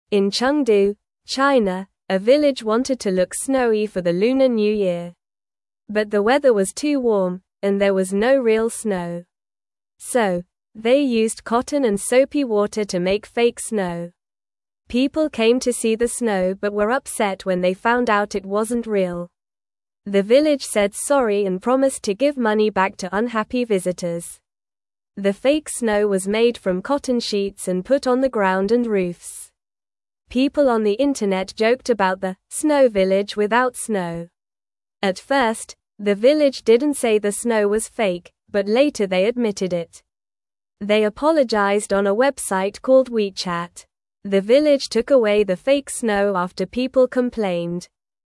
Normal
English-Newsroom-Beginner-NORMAL-Reading-Village-Makes-Fake-Snow-for-Lunar-New-Year-Fun.mp3